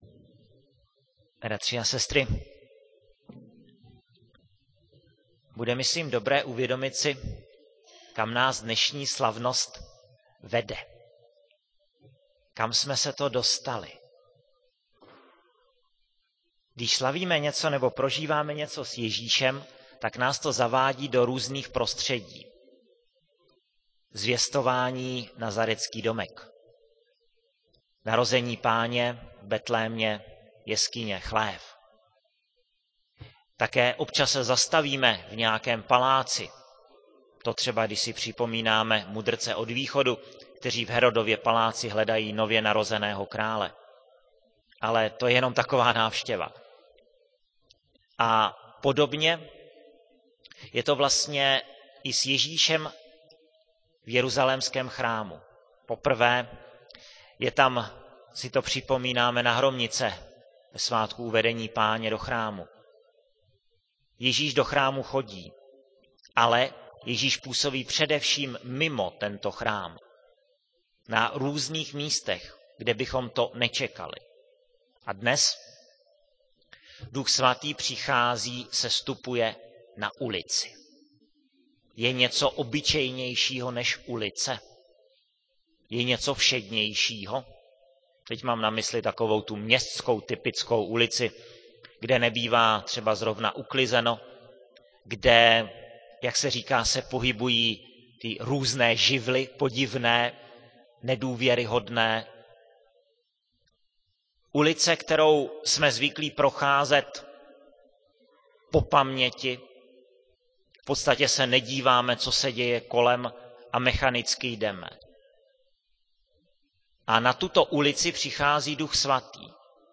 Rubrika Homilie
11.05.2008 - neděle , kostel sv. Jakuba ve Veverské Bítýšce